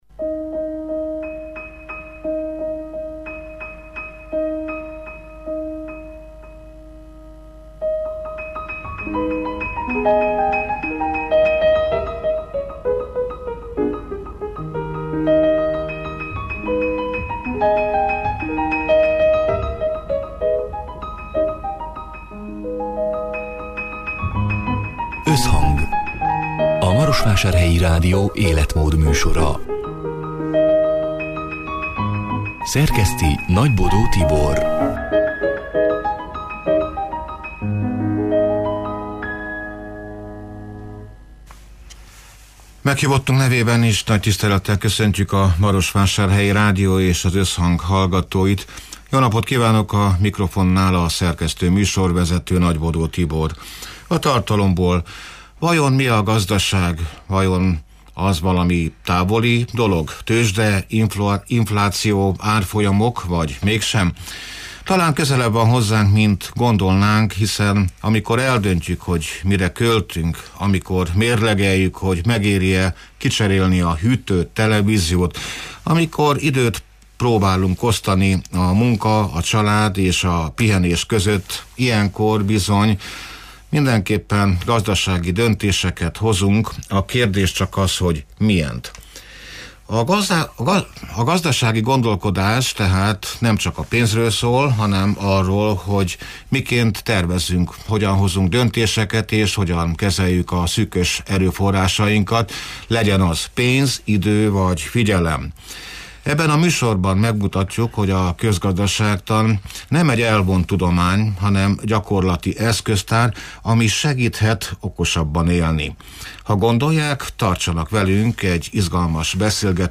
(elhangzott: 2025. április 30-án, szerdán délután hat órától élőben)
Ebben a műsorban megmutatjuk, hogy a közgazdaságtan nem egy elvont tudomány, hanem gyakorlati eszköztár, ami segíthet okosabban élni. Tartsanak velünk egy izgalmas beszélgetésre arról, hogy miért éri meg közgazdászként is gondolkodni – még akkor is, ha az ember nem ül a tőzsde előtt nap mint nap!